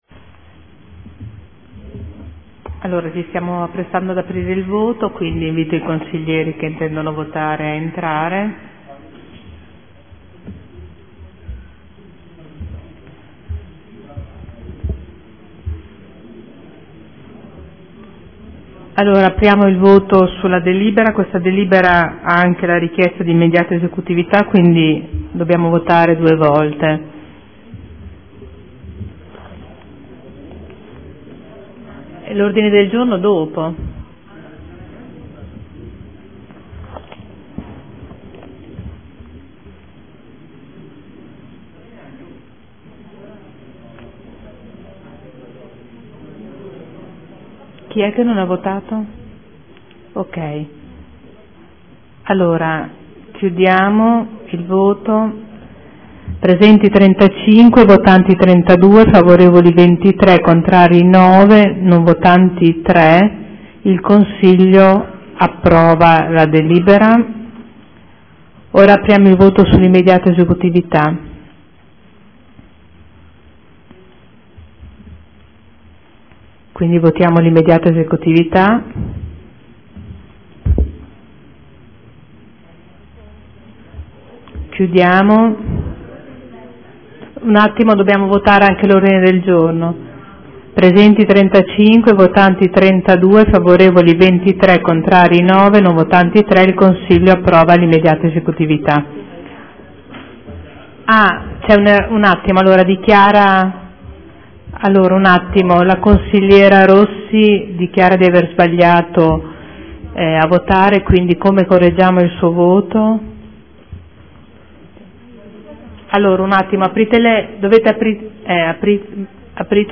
Caterina Liotti — Sito Audio Consiglio Comunale